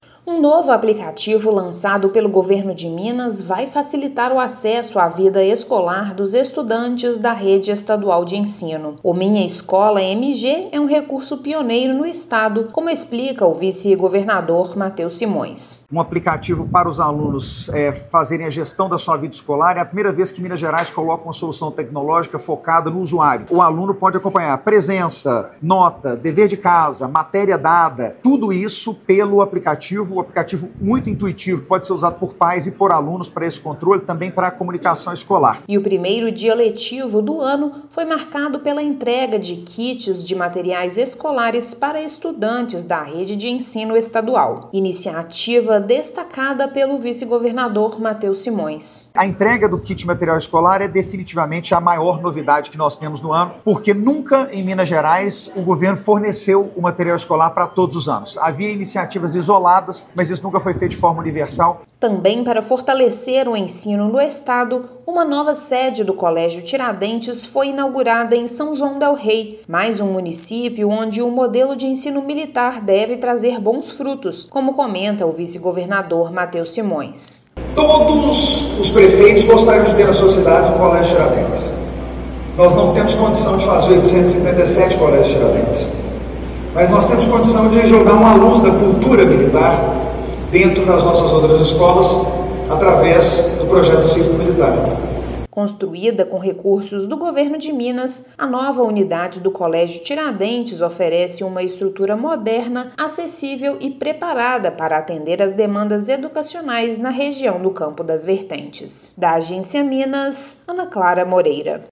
Minuto Agência Minas da semana destaca lançamento de novo aplicativo, entrega de kits de material escolar e a nova unidade do Colégio Tiradentes em São João del-Rei. Ouça matéria de rádio.